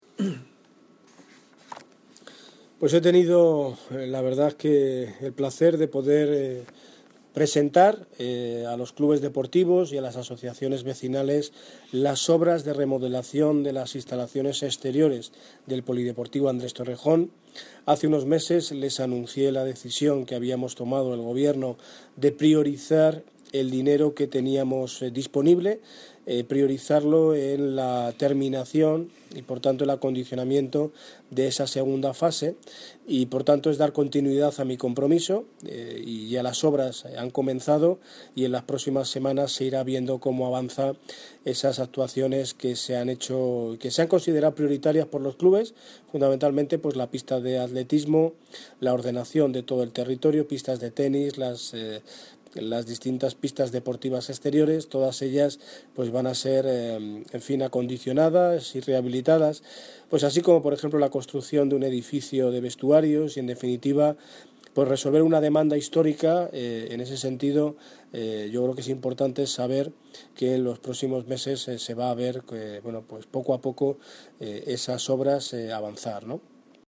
Audio - Daniel Ortiz (Alcalde de Móstoles) Sobre polideportivo Andres Torrejon